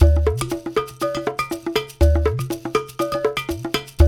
120 -UDU 02L.wav